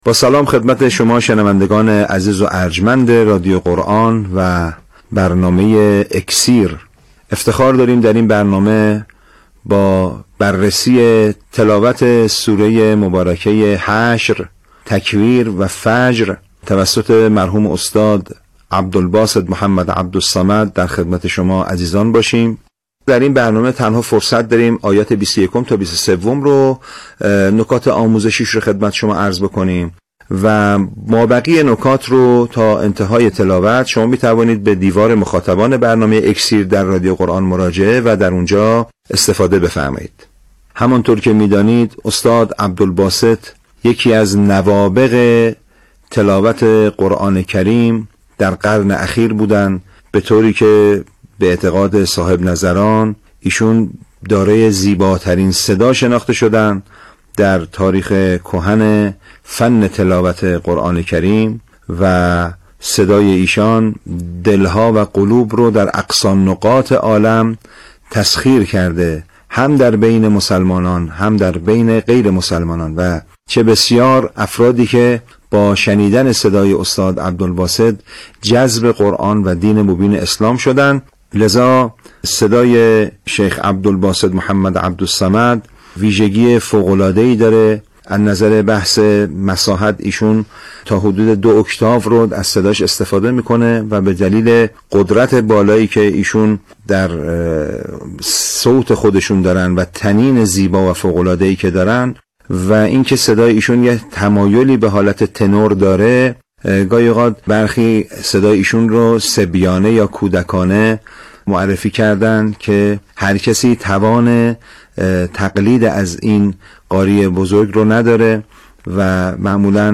این تلاوت در عراق و در کاظمین اجرا شده است و خود استاد هم از این تلاوت به شایستگی یاد می‌کند.
به دلیل قدرت بالایی که در صوت خود داشت و طنین زیبای آن و این‌که صدای ایشان تمایلی به تِنور دارد، گاهی، برخی صدای ایشان را صبیانه یا کودکانه معرفی کردند.
در این تلاوت، عبدالباسط با مقام بیات آغاز کرده است و بیات نوا خوانده است و به سمت صبا انتقالاتی داشته و بعد به بخش چند بار تکرار خودش «لَوْ أَنْزَلْنَا»ی معروف رسیده است.